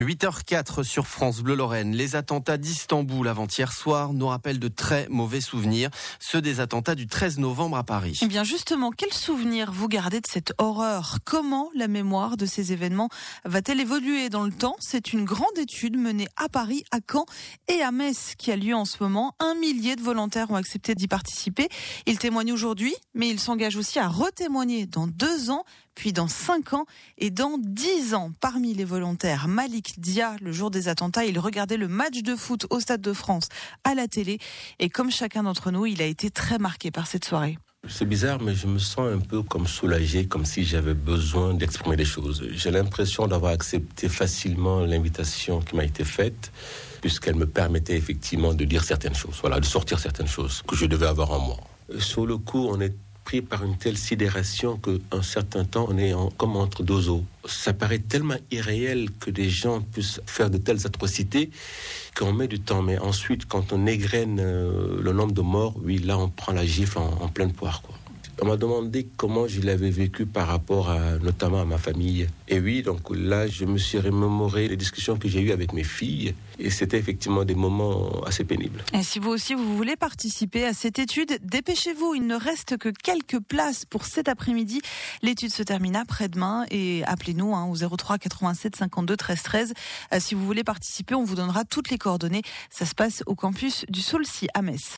France Bleu Lorraine : deux reportages sur le programme 13-Novembre
Deux reportages sur le programme 13-Novembre on été diffusés sur France Bleu Lorraine.